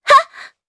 Yuria-Vox_Attack3_jp.wav